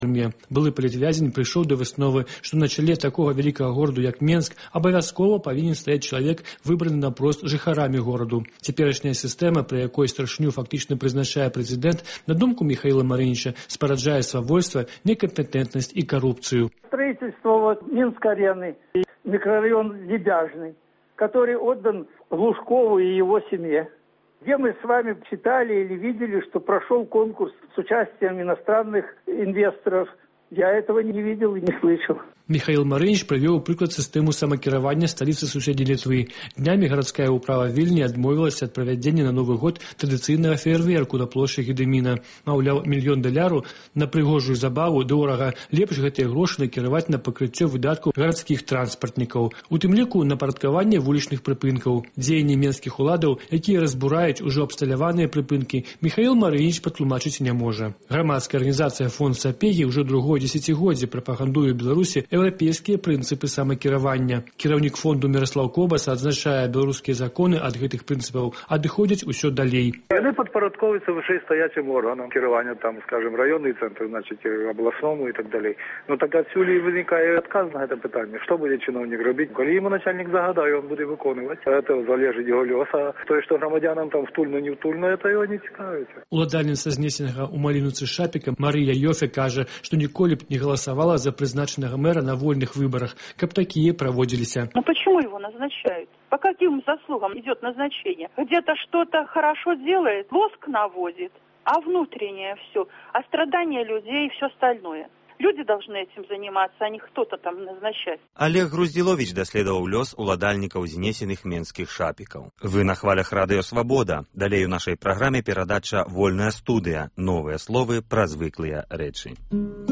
Гутаркі без цэнзуры зь дзеячамі культуры й навукі